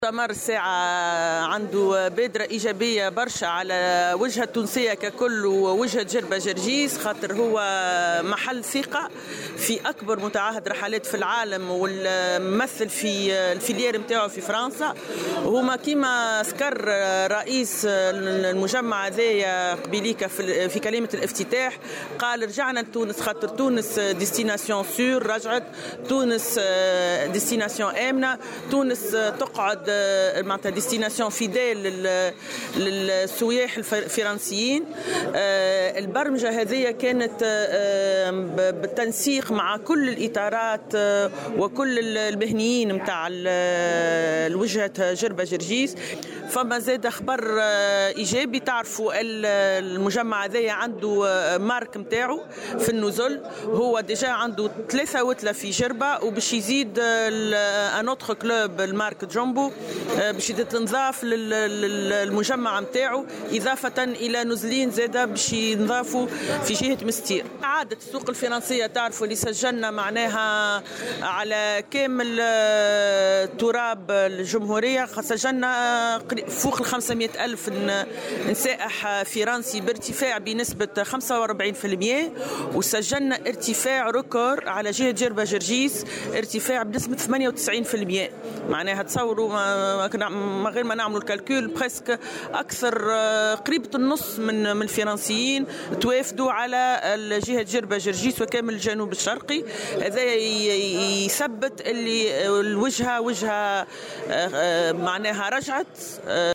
وأضافت في تصريح لمراسلة "الجوهرة أف أم" على هامش أشغال المؤتمر السنوي لمتعهد الرحلات "طوماس كوك فرنسا" الذي ينعقد بجزيرة جربة أن المؤتمر سيكون له بوادر ايجابية على الجهة، وسيساهم في الترويج للوجهة التونسية.